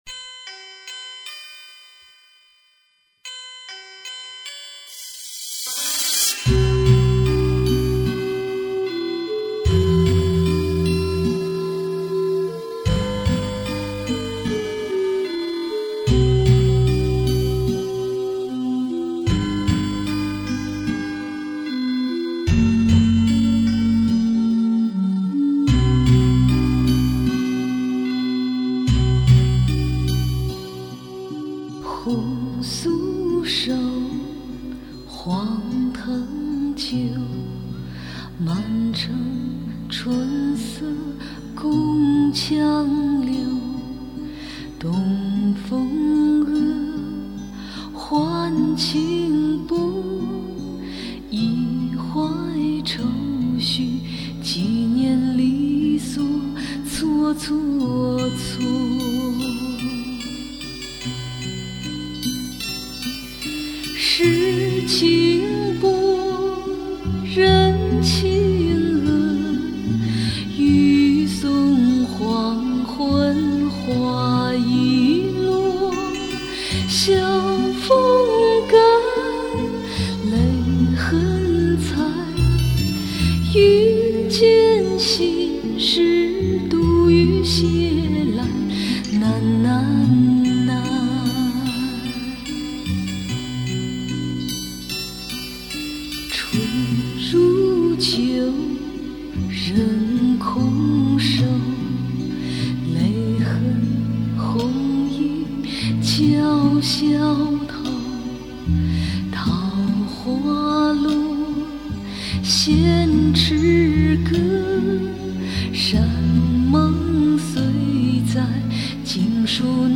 [2005-8-9]一曲《《钗头凤》伴随悲伤而略带几分凄凉的女声。。。